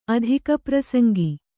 ♪ adhikaprasaŋgi